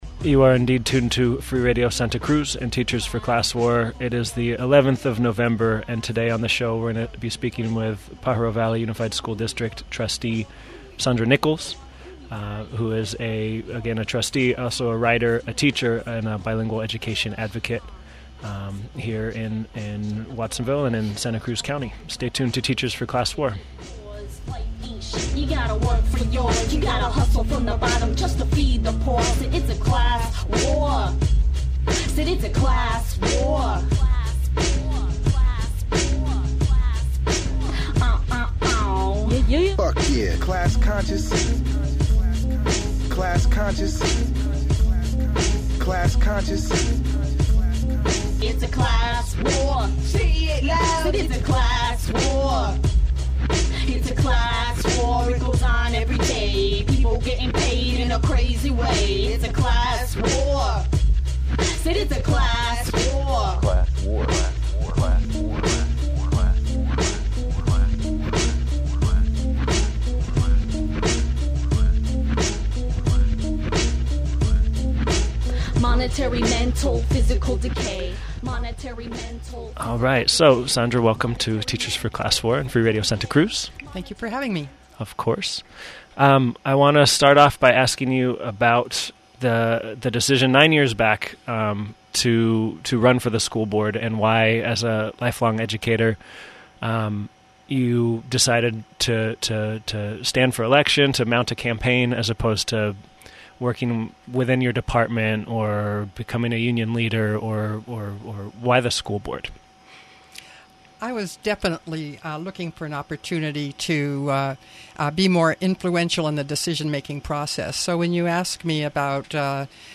Monday we spoke with Sandra Nichols, school board trustee for almost ten years in Pajaro. Sandra talked about why she ran for the elected office, and the political battles waged over the course of her time on the board over issues like bilingual education, transparency, budget priorities, military recruitment, and more. 57:48 mono MP3.